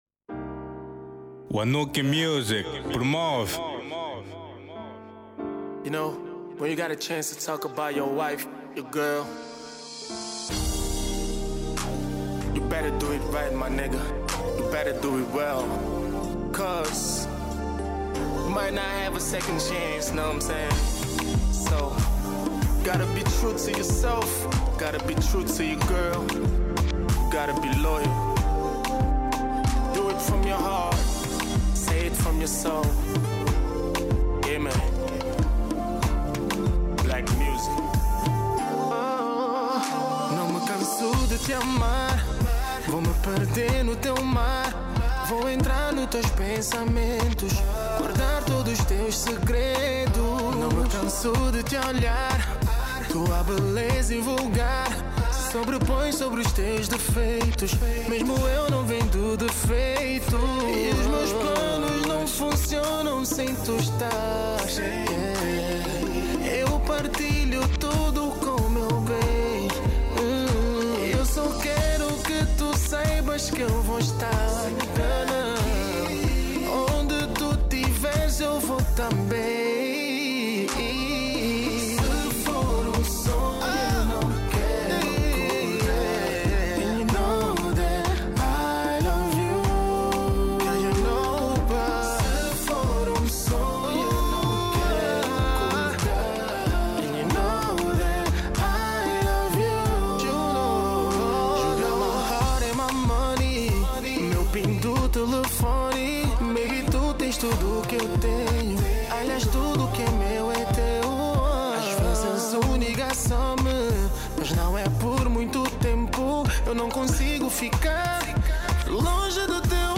Genero: Kizomba